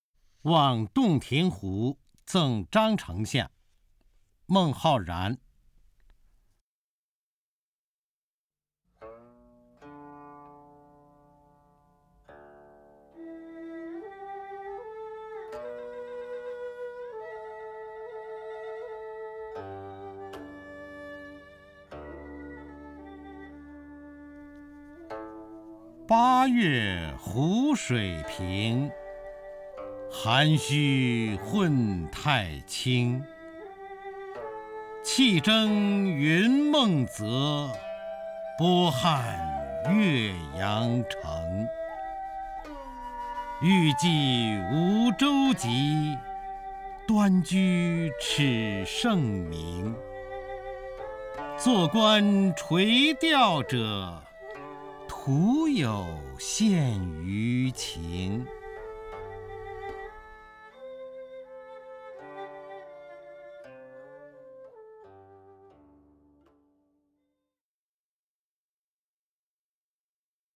方明朗诵：《望洞庭湖赠张丞相》(（唐）孟浩然)
名家朗诵欣赏 方明 目录